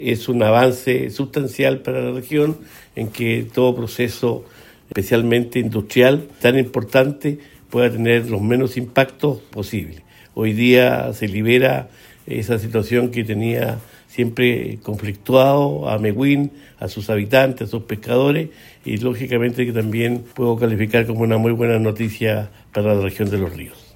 El gobernador regional y presidente de la Crubc Los Ríos, Luis Cuvertino, afirmó que un ducto al mar con fines industriales es incompatible en Mehuín, más allá de los aspectos medioambientales, valorando lo resuelto.